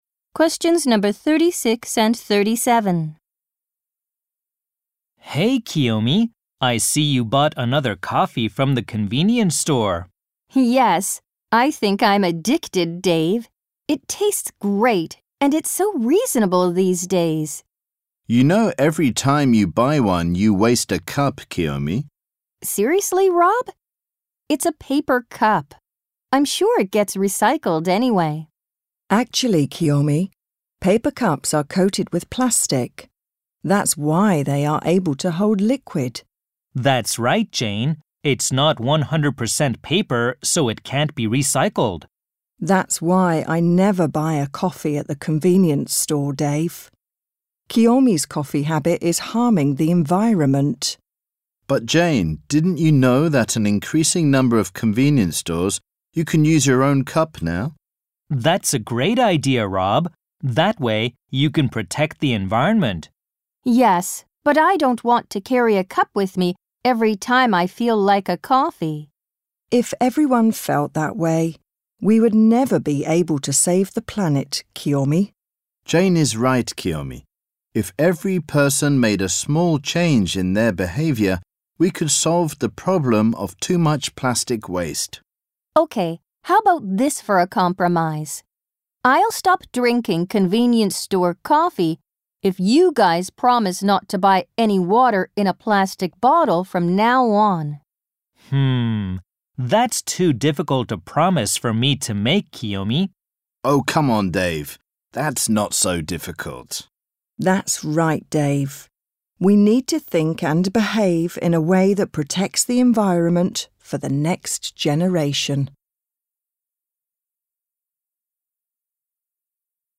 ○共通テストの出題音声の大半を占める米英の話者の発話に慣れることを第一と考え，音声はアメリカ（北米）英語とイギリス英語で収録。
第6問B 問36・37（アメリカ（北米）英語＋イギリス英語）